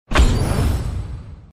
ui_interface_133.wav